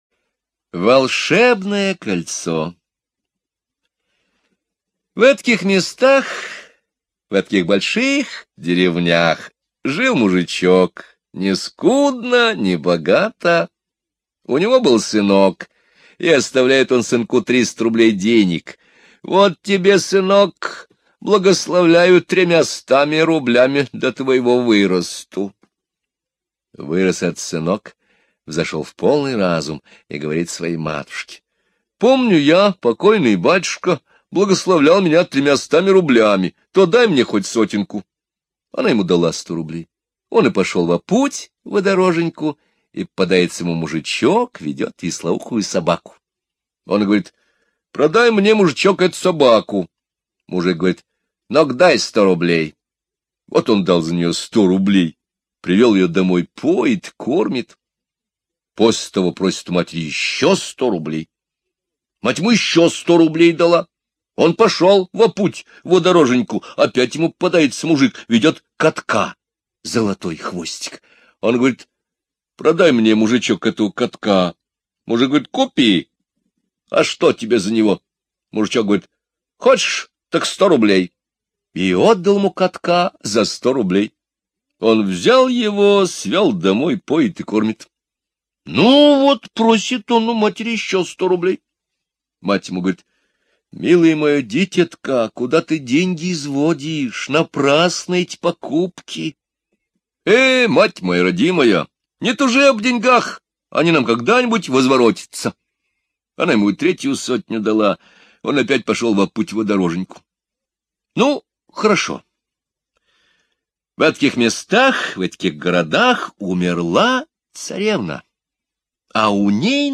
Волшебное кольцо - русская народная аудиосказка. Сказка о добром юноше Мартынке, который место того, чтобы купить хлеб, спас кота и собаку...